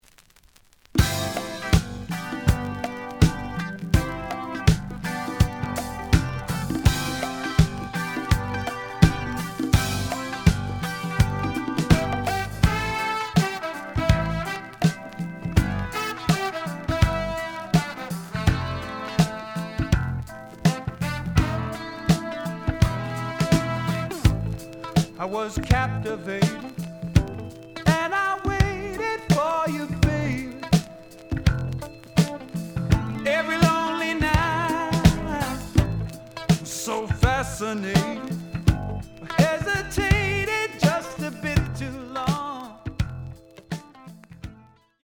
The audio sample is recorded from the actual item.
●Genre: Jazz Rock / Fusion